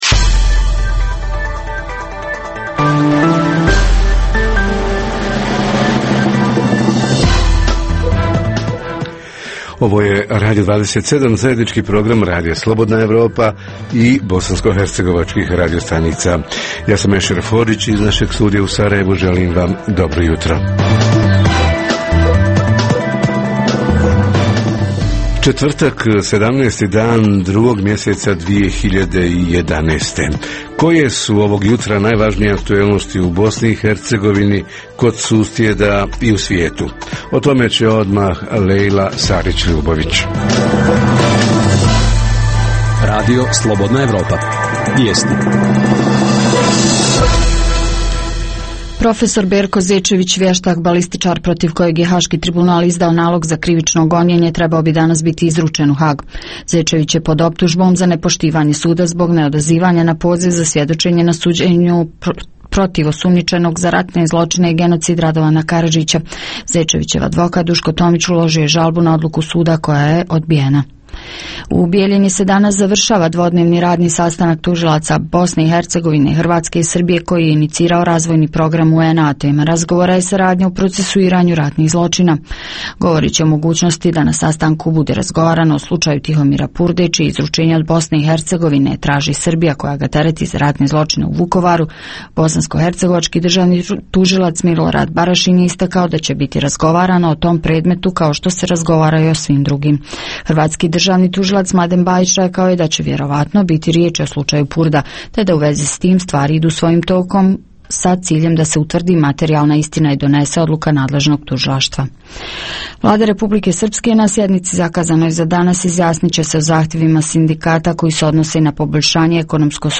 - Redovna rubrika Radija 27 četvrtkom je “Radio ordinacija”. - Redovni sadržaji jutarnjeg programa za BiH su i vijesti i muzika.